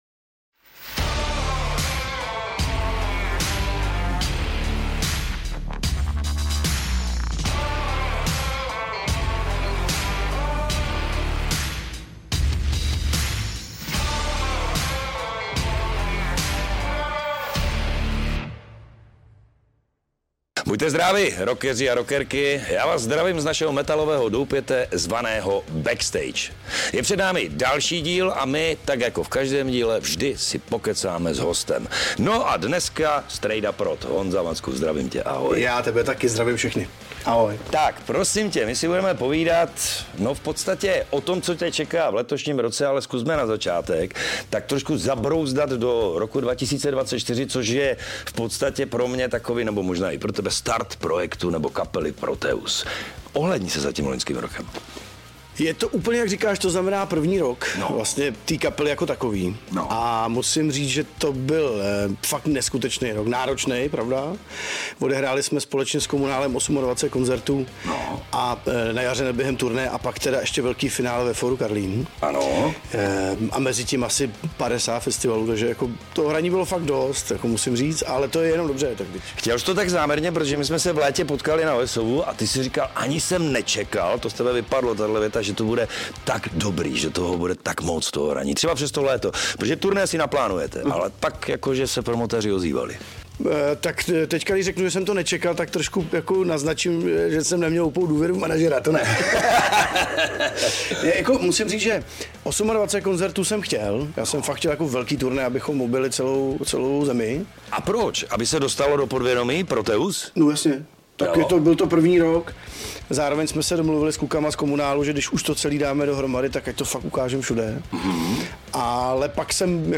V dalším díle podcastu Projekt Backstage vás vezmeme do zákulisí punk rockové kapely Bijouterrier z Nitry! Na letošním festivalu Holba Rock na grilu jsme vyzpovídali členy této formace, kteří s námi sdíleli nejen svůj pohled na hudbu a život, ale také pořádnou dávku humoru a nadsázky.
Tento rozhovor je stejně nečekaný a energický jako jejich koncerty!